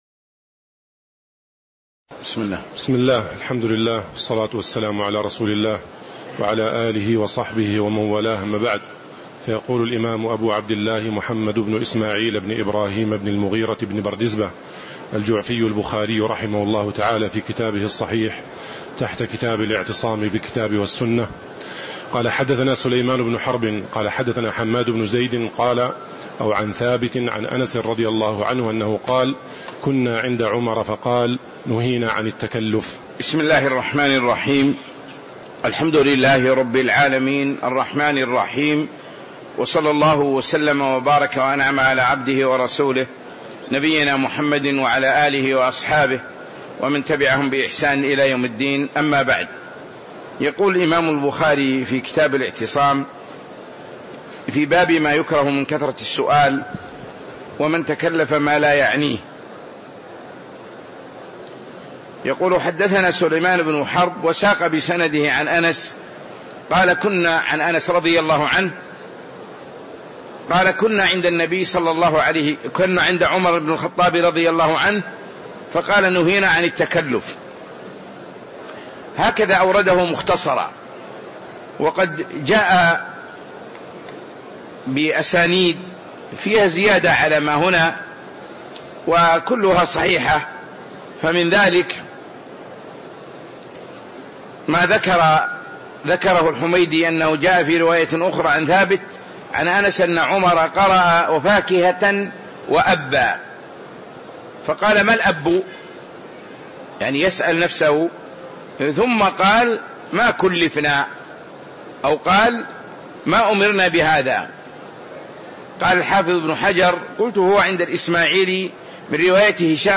تاريخ النشر ١٦ جمادى الآخرة ١٤٤٦ هـ المكان: المسجد النبوي الشيخ